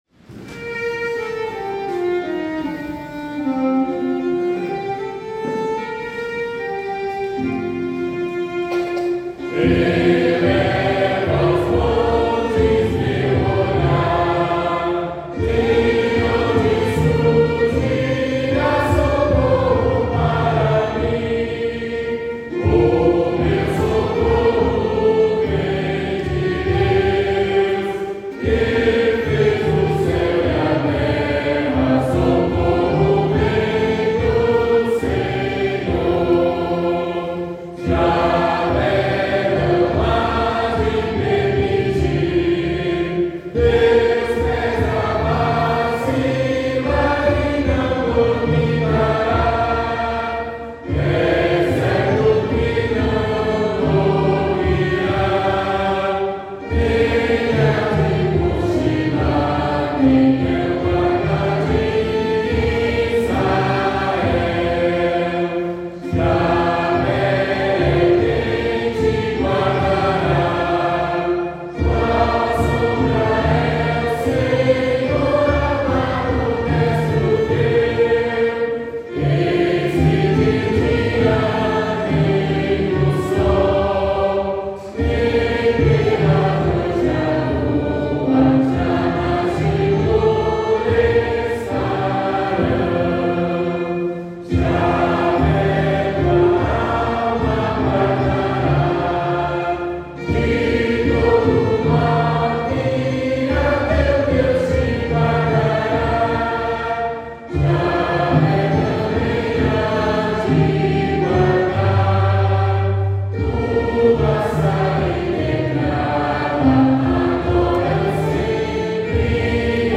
Modo: hipomixolídico
salmo_121A_cantado.mp3